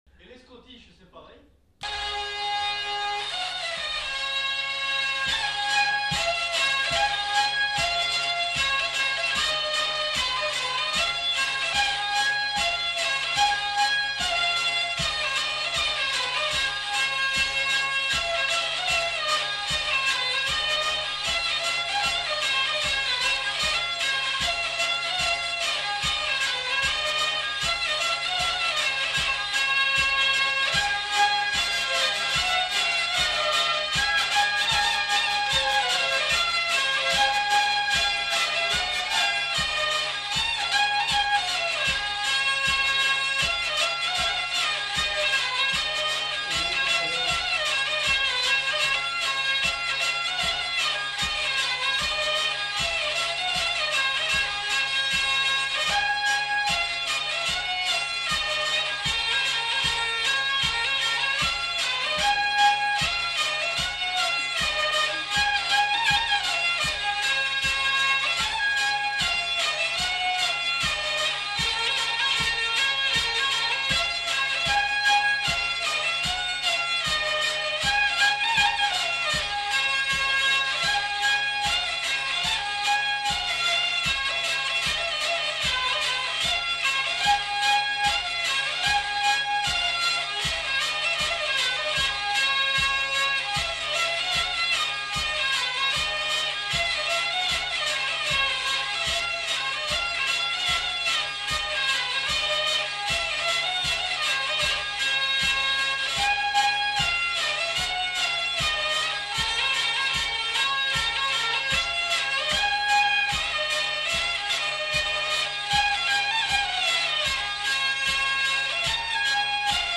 Aire culturelle : Gabardan
Lieu : Vielle-Soubiran
Genre : morceau instrumental
Instrument de musique : vielle à roue
Danse : scottish